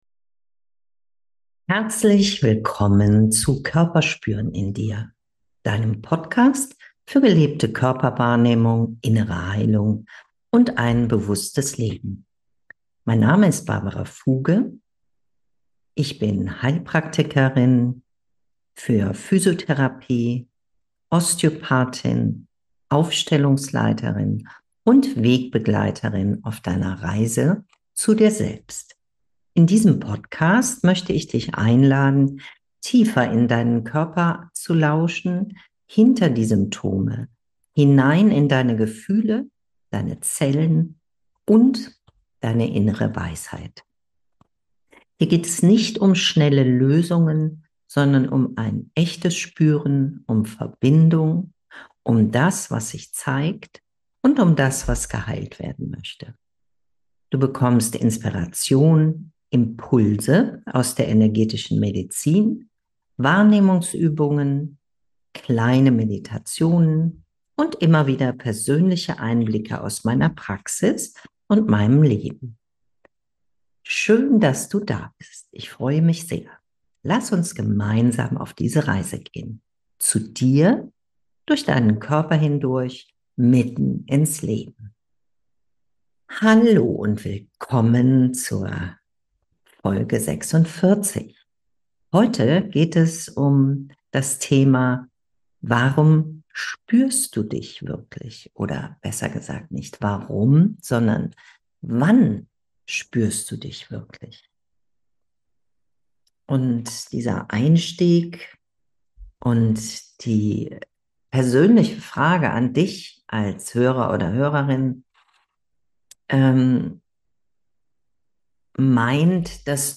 Eine kleine Meditation wartet am Ende der Folge auf dich – zum Nachspüren und Ankommen in dir selbst.